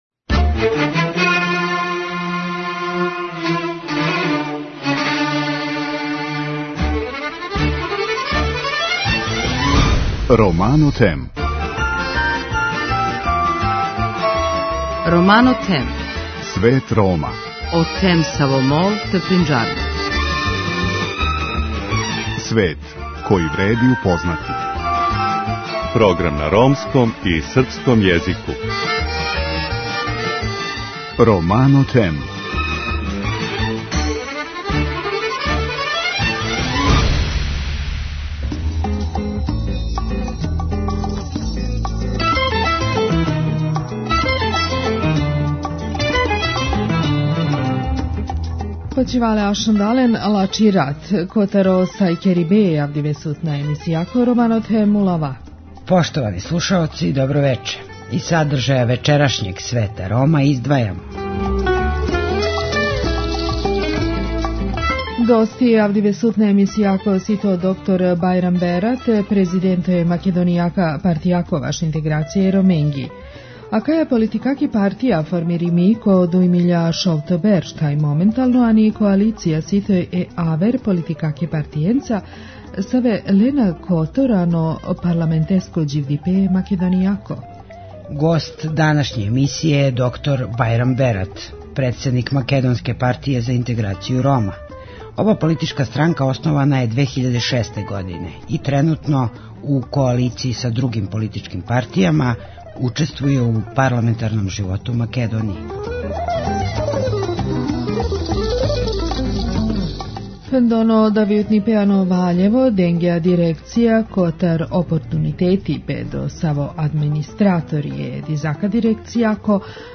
Гост данашње емисије је др Бајрам Берат, председник македонске Партије за интеграцију Рома.